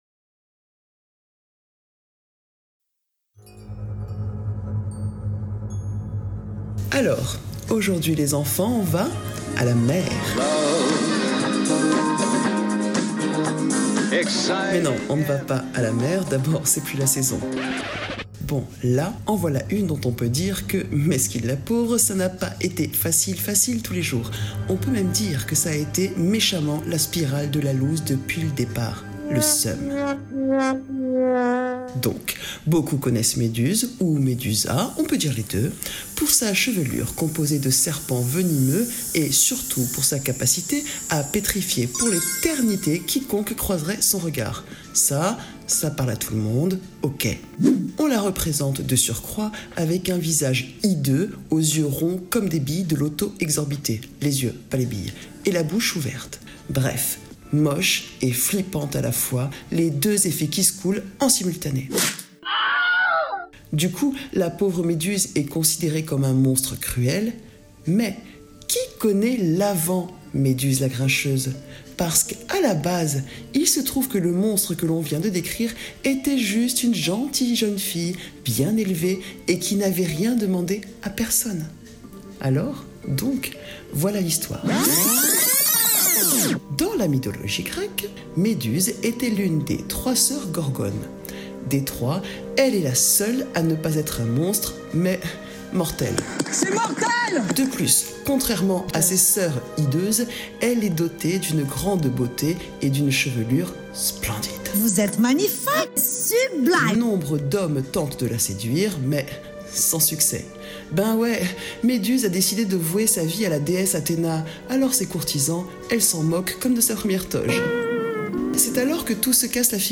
Découvrez l’histoire de Méduse dans son univers sonore.